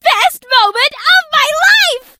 colette_lead_vo_01.ogg